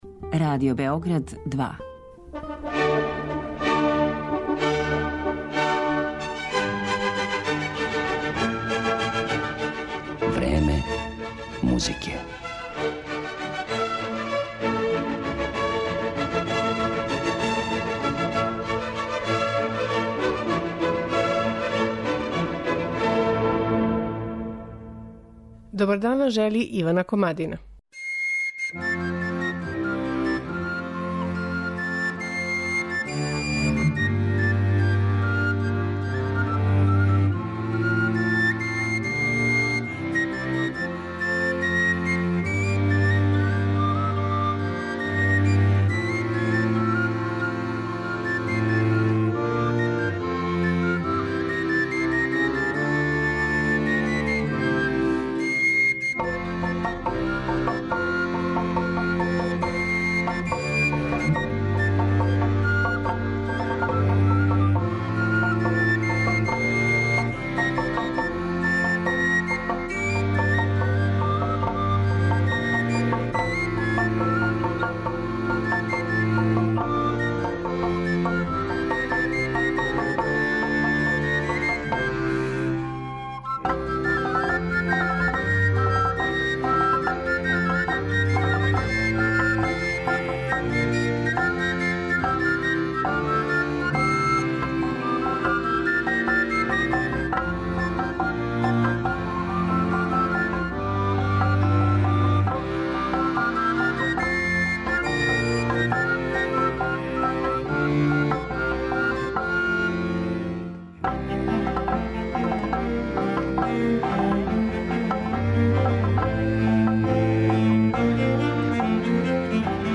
Чалапарта је најпрепознатљивији баскијски инструмент: масивни дрвени сто са неколико дасака положених уздужно, по којима се свира великим дрвеним батовима или чуњевима.